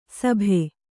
♪ sabhe